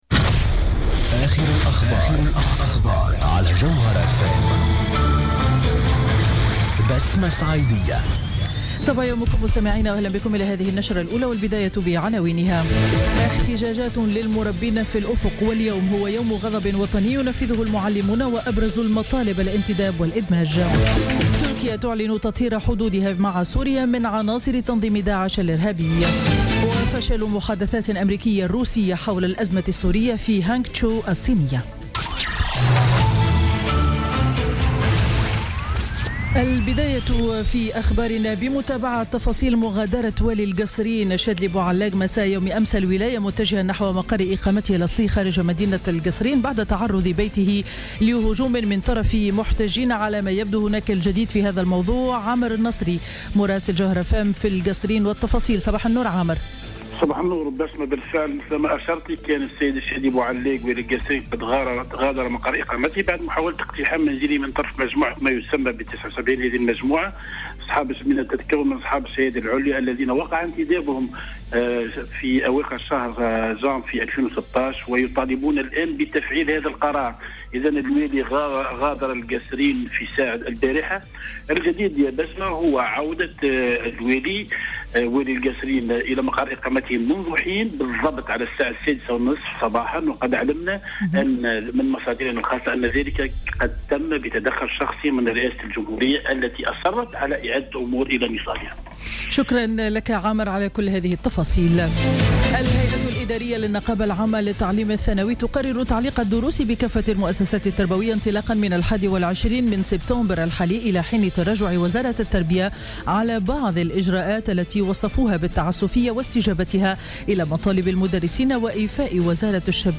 نشرة أخبار السابعة صباحا ليوم الاثنين 5 سبتمبر 2016